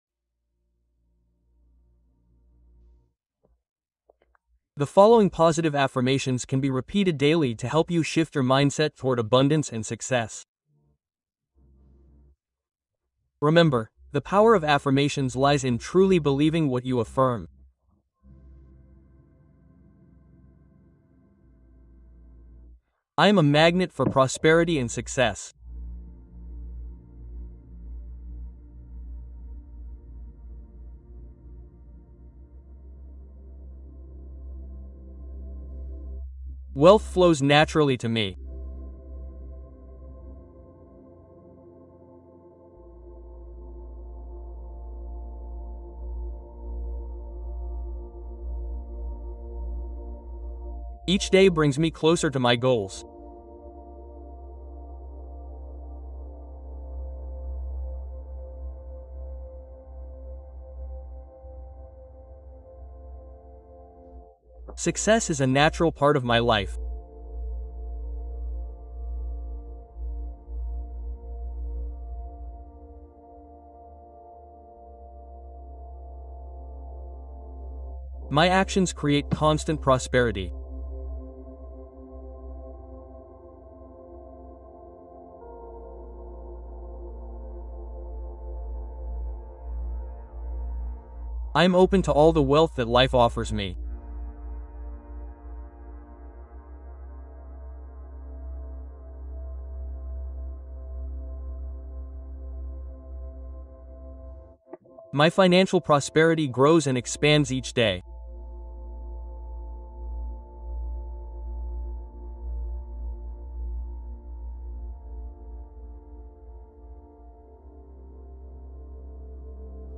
Nuit de réconfort : hypnose douce pour calmer l’activité mentale avant le sommeil